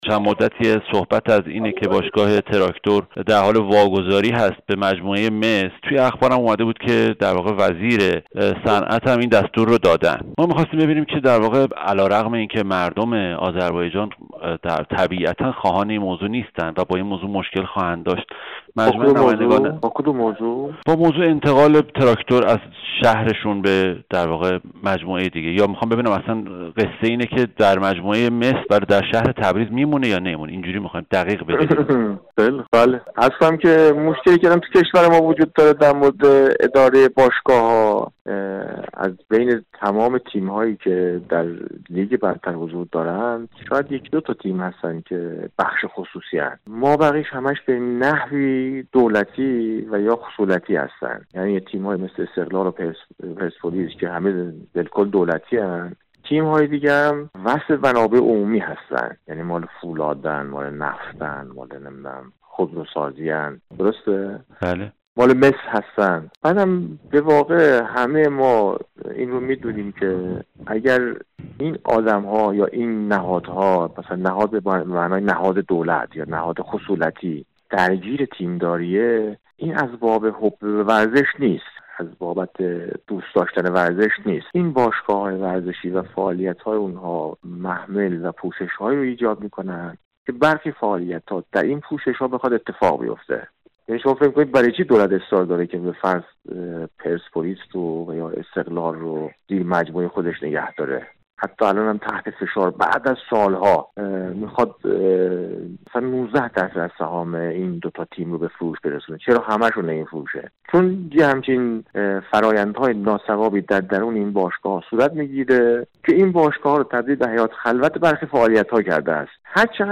از انتقال باشگاه به استان های دیگر تا تغییر نام از تراکتور به مس که احمد علیرضا بیگی نماینده مردم تبریز ، اسکو و آزادشهر در مجلس در گفتگوی اختصاصی با رادار اقتصاد به همه این نگرانی ها پاسخ داده است: